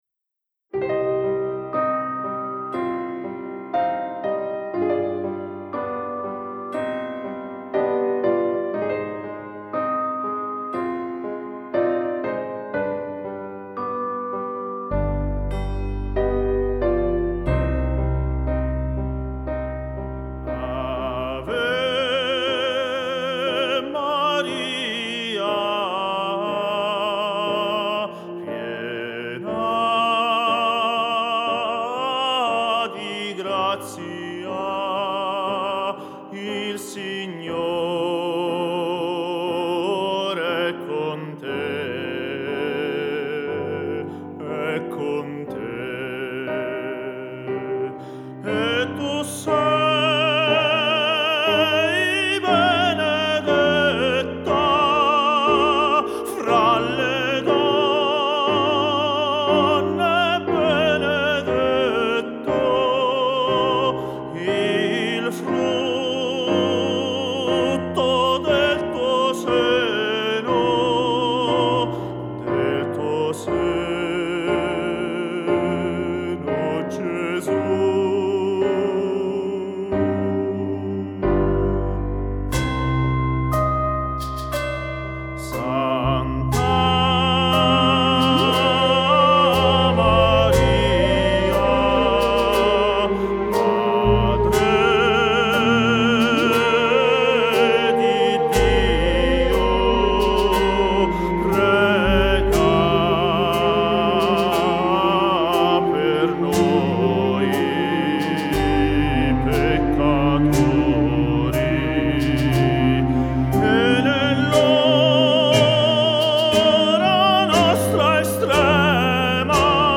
per Soprano/Tenore ed Orchestra di Fiati